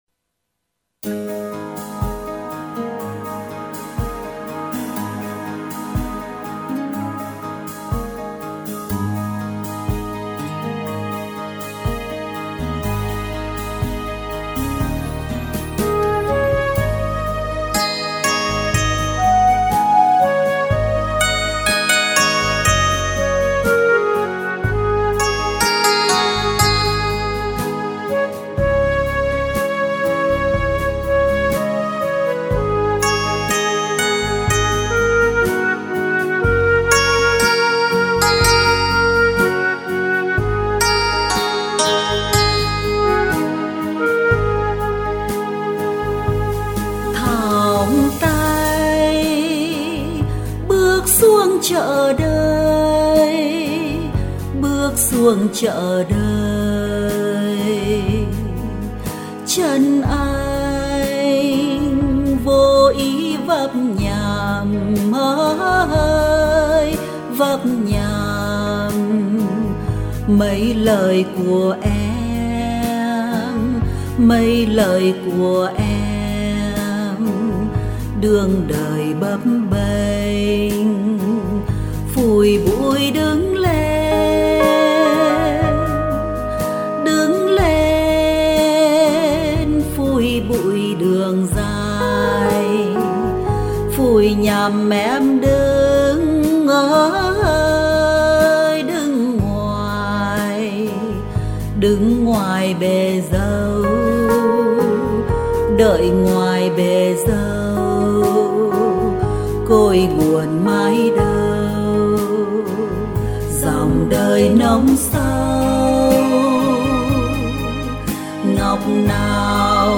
Hòa âm và hát